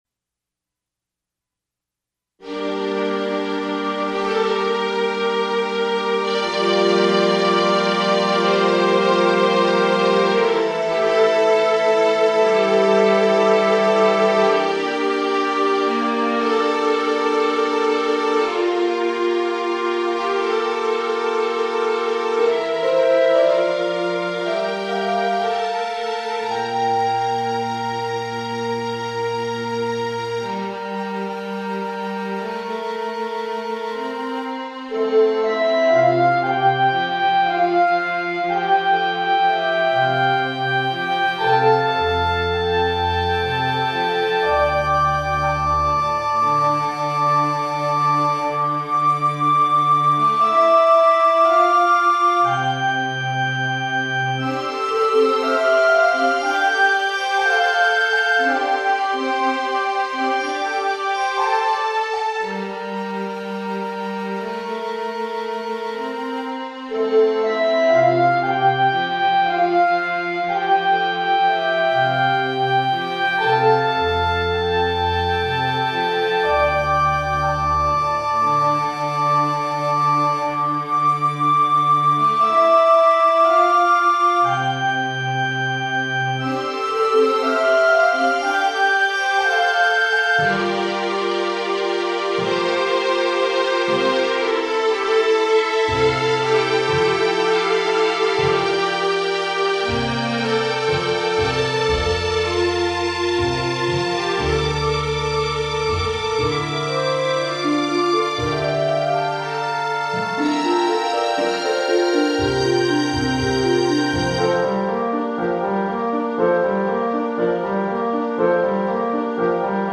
Symphonic music for film